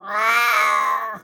SFX_Cat_Meow_02.wav